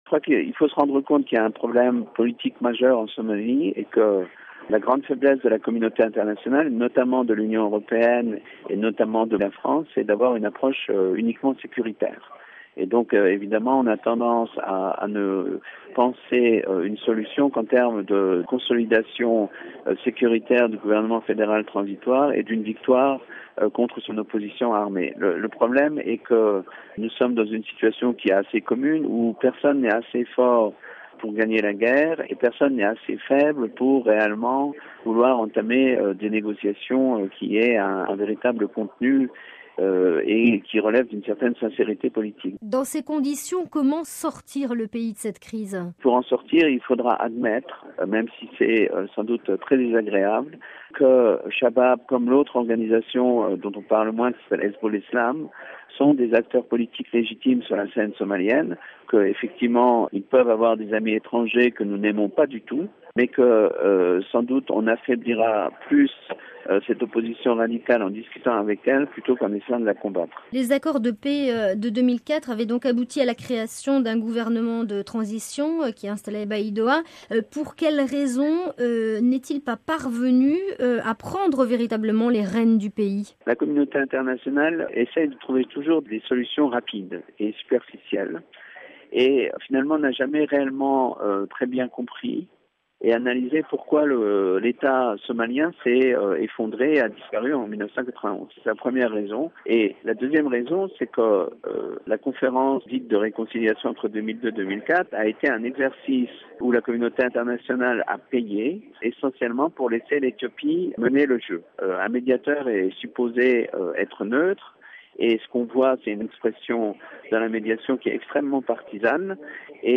L’analyse
chargé de recherches au CNRS joint à Nairobi, au Kenya.